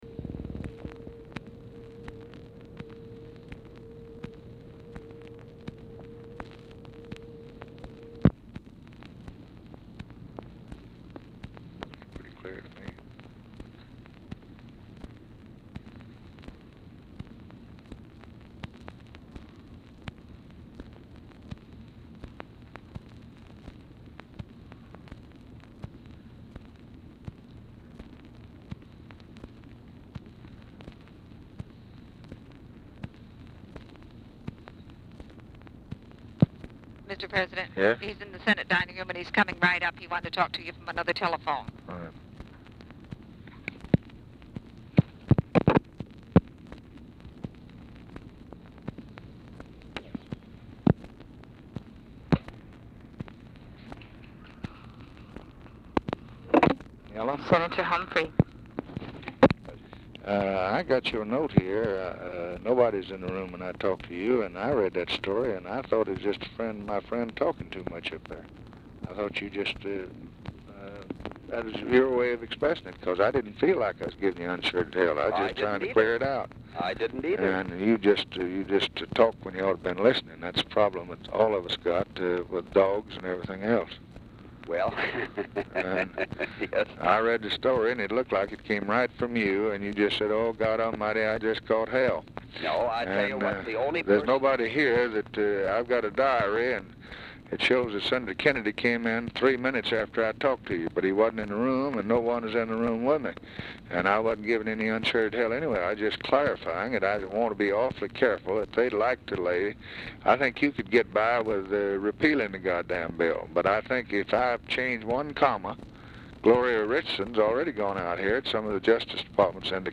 Oval Office or unknown location
OFFICE CONVERSATION PRECEDES CALL; LBJ ON HOLD 1:00; SELECTED AS A HIGHLIGHT OF THE TELEPHONE CONVERSATION COLLECTION
Telephone conversation
Dictation belt